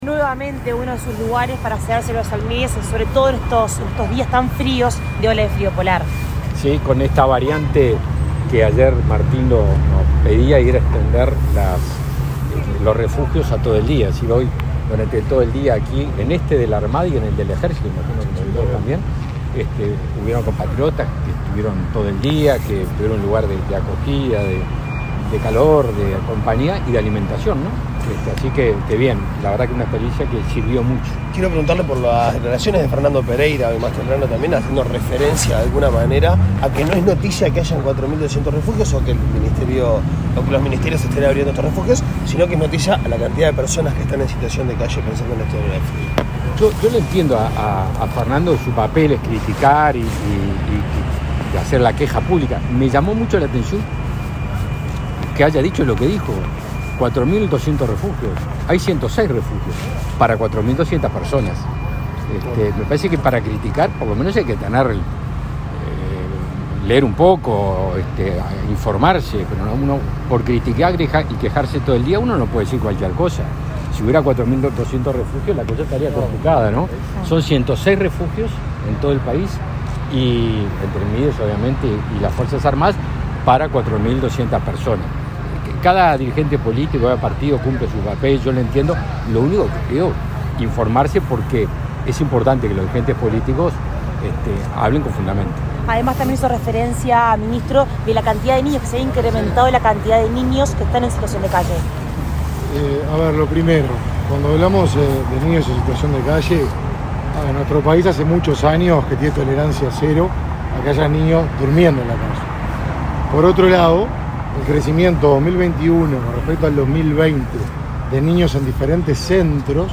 Declaraciones a la prensa de los ministros de Defensa Nacional, Javier García, y de Desarrollo Social, Martín Lema
Declaraciones a la prensa de los ministros de Defensa Nacional, Javier García, y de Desarrollo Social, Martín Lema 31/05/2022 Compartir Facebook X Copiar enlace WhatsApp LinkedIn En el marco del Plan Frío Polar, los ministros de Defensa Nacional, Javier García, y de Desarrollo Social, Martín Lema, visitaron, este 31 de mayo, el refugio que funciona en un local de la Armada Nacional, ubicado en rambla portuaria de Montevideo. Tras el recorrido, efectuaron declaraciones a la prensa.